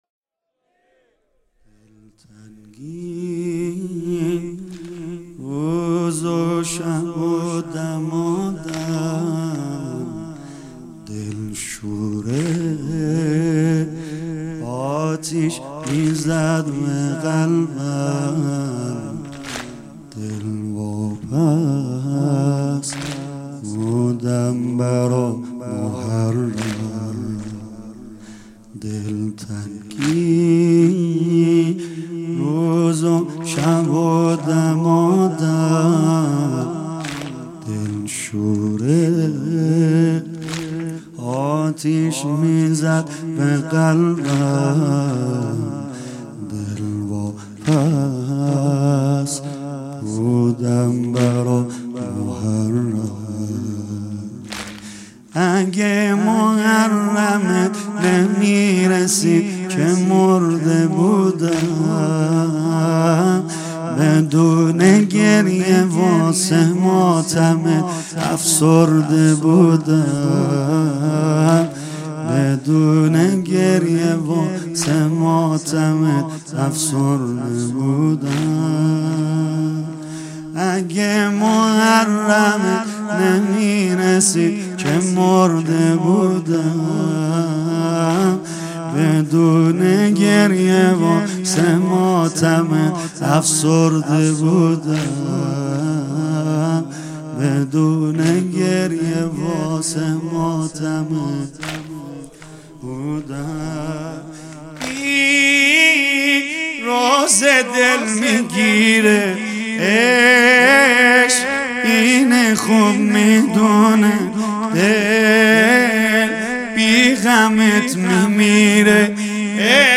مداحی جدید سید رضا نریمانی شب اول محرم 99.05.31 هیات فداییان حسین علیه السلام اصفهان